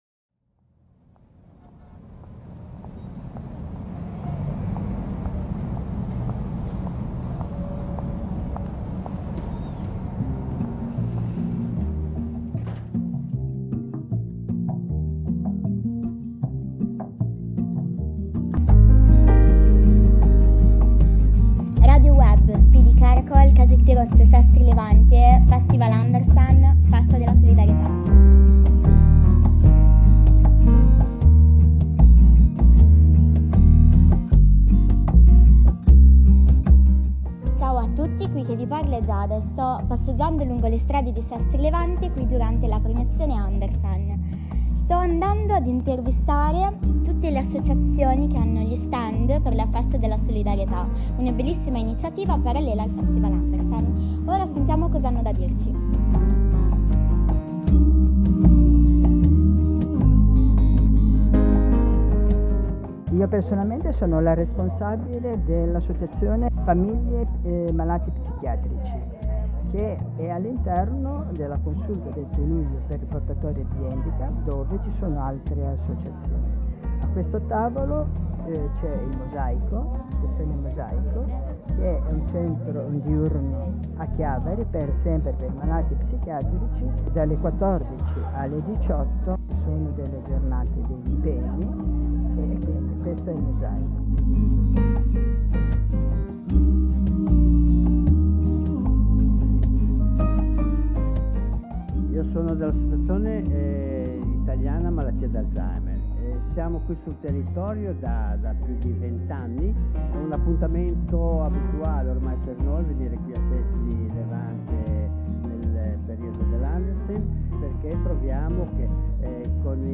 Ripercorriamo con brevi interviste l'intera "carovana" di banchetti, in cui le associazioni mettono a disposizioni informazioni alla comunità. Si parla di solidarietà!!!